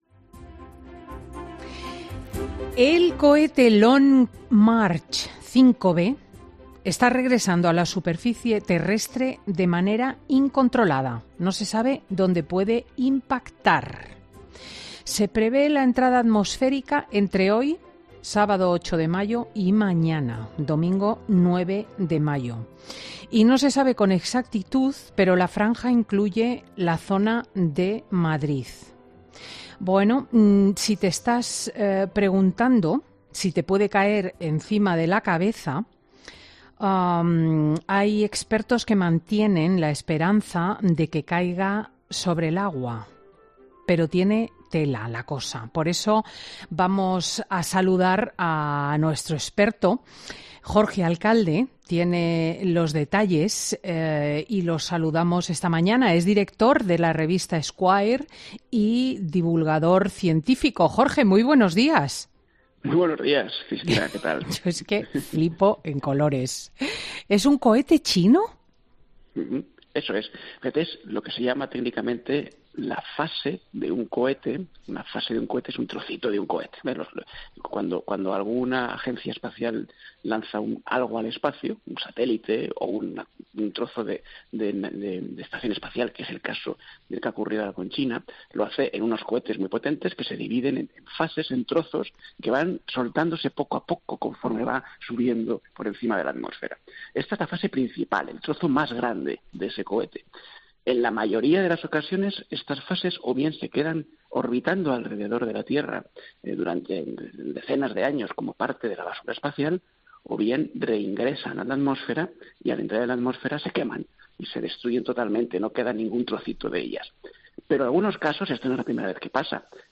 Jorge Alcalde responde a las cuestiones cruciales sobre dónde caerá el Long March 5b: ¿impactará en Madrid?
Así, el colaborador aclara a Cristina López Schlichting dónde es más que probable que impacte el trozo del artefacto y cuándo lo hará.